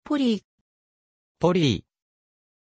Pronunciation of Polly
pronunciation-polly-ja.mp3